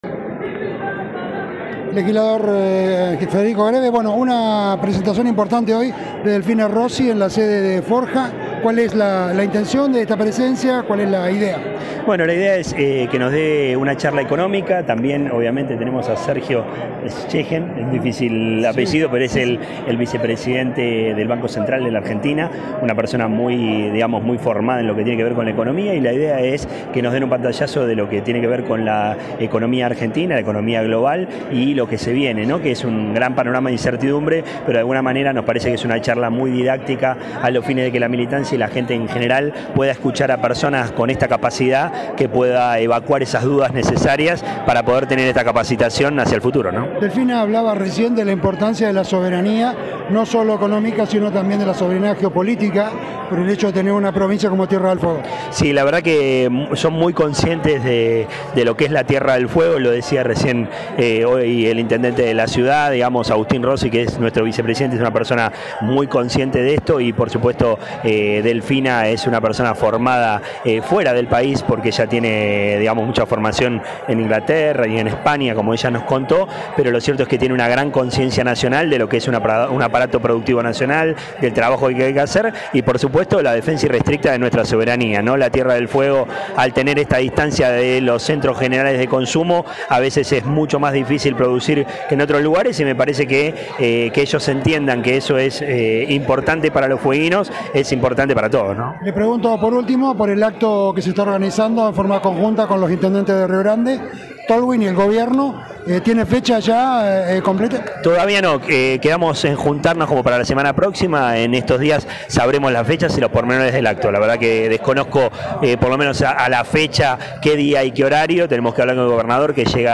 El legislador Federico Greve, FORJA, en dialogo con La Licuadora, analizó la visita de la economista Delfina Rossi, Presidenta del Banco Ciudad y quien además dio una charla sobre soberanía económica y montería en la cede del partido de Calle Malvinas Argentinas, sobre la actualidad de la economía argentina, la economía global y lo que se viene que es una gran incertidumbre, pero es una charla didáctica a fin de que la militancia y la gente en general puedan escuchar a personas con esta capacidad, que pueda evacuar esas dudas necesarias para tener esta capacitación con miras al futuro.